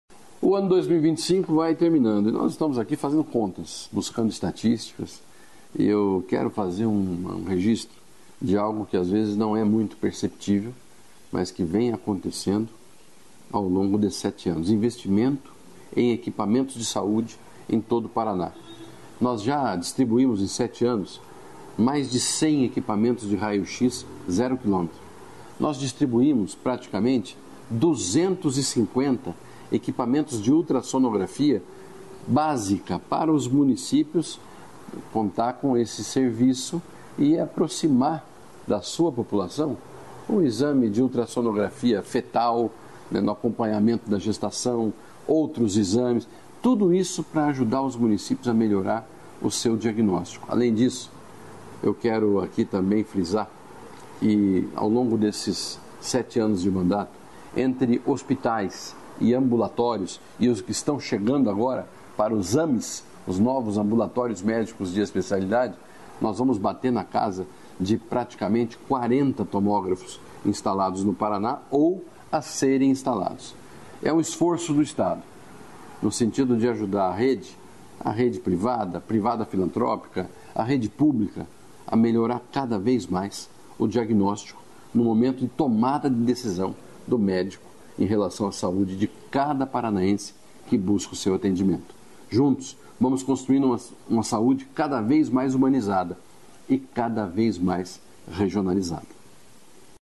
Sonora do secretário da Saúde, Beto Preto, sobre os investimentos em equipamentos